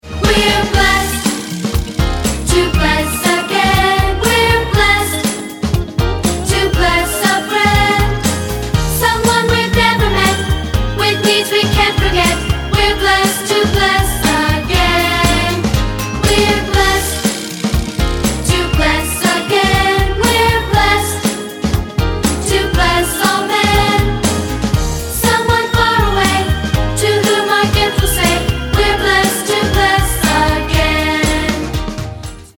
A joyous march